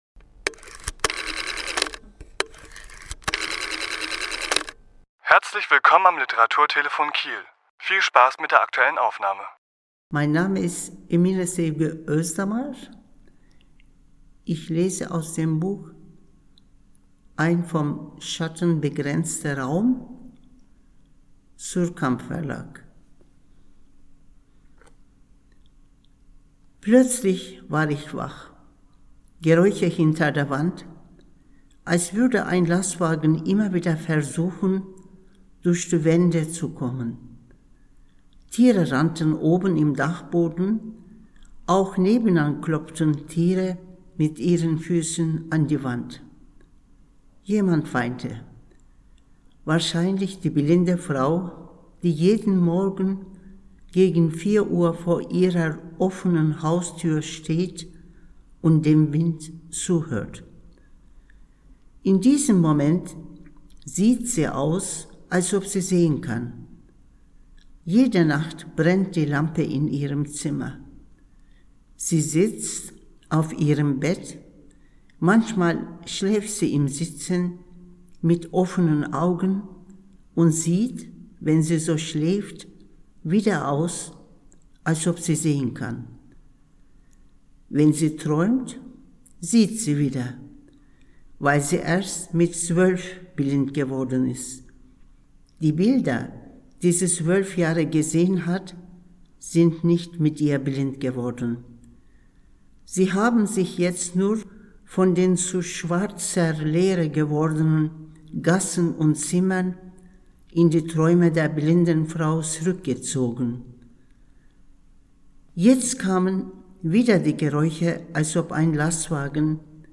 Autor*innen lesen aus ihren Werken
Die Aufnahme entstand im Rahmen einer Lesung in der Reihe Sprachkunst am 20.12.2022 im Kesselhaus der Muthesius Kunsthochschule.